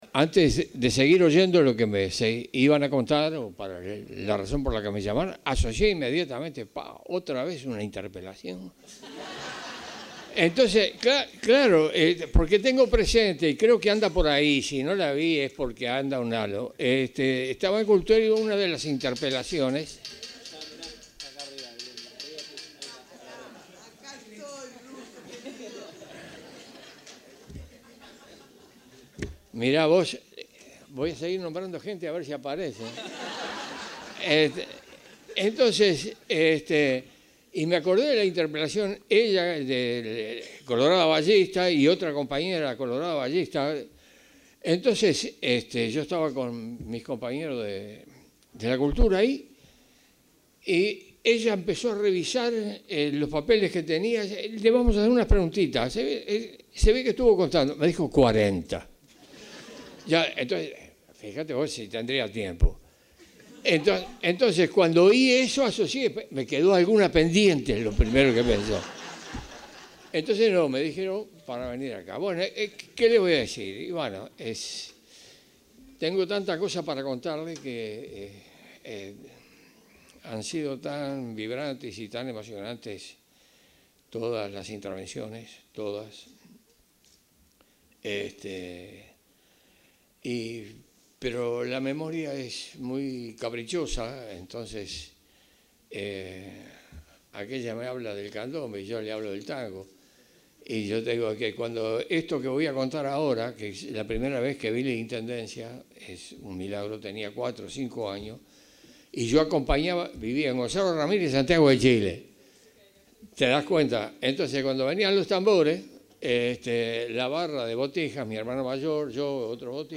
Palabras del escritor, poeta y dramaturgo Mauricio Rosencof 31/10/2025 Compartir Facebook X Copiar enlace WhatsApp LinkedIn La Junta Departamental de Montevideo le realizó un homenaje al escritor, poeta y dramaturgo uruguayo Mauricio Rosencof, al que asistió el presidente de la República, Yamandú Orsi. En la ocasión, el autor cerró el encuentro con sus palabras.